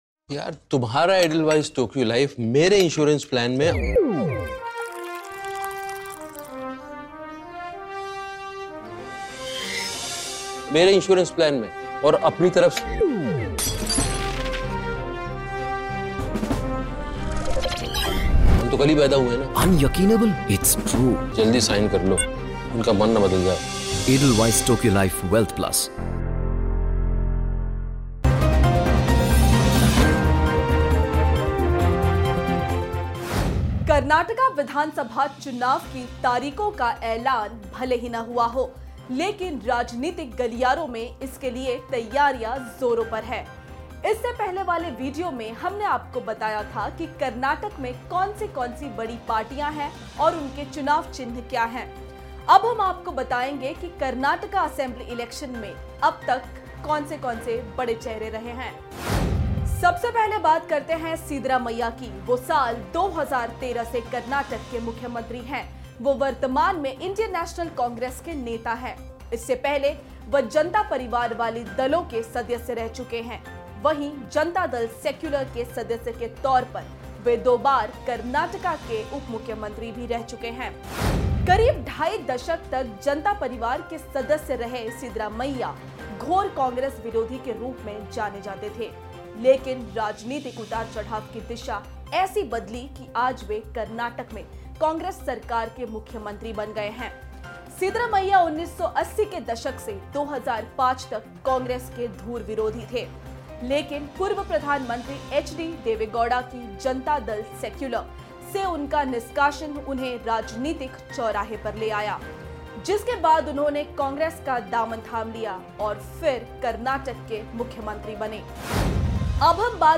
News Report / Karnataka Assembly Election 2018: ये हैं कर्नाटक के राजनीतिक धुरंधर